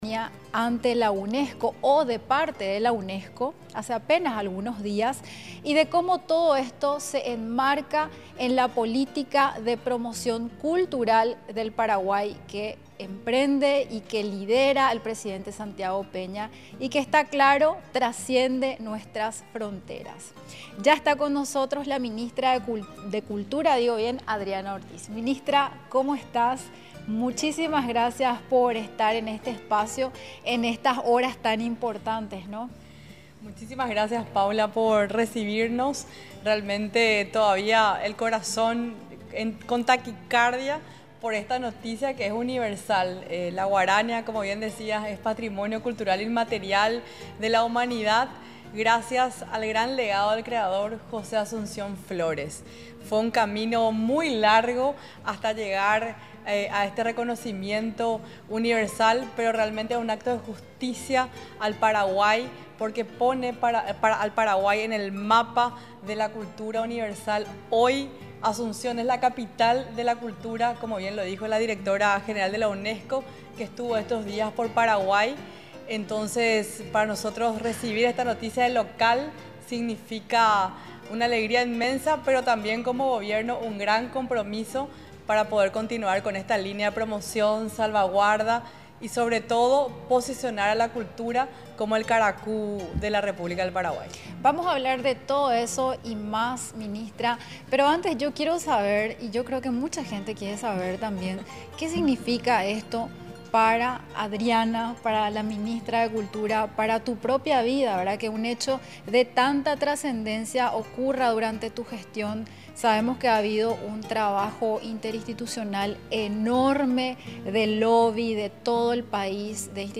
El ñanduti fue presentado como las prácticas tradicionales de las ciudades de Itauguá, Guarambaré y de la compañía Tuyucuá de Pirayú, detalló la ministra en conversación con la vocera de Gobierno, Paula Carro.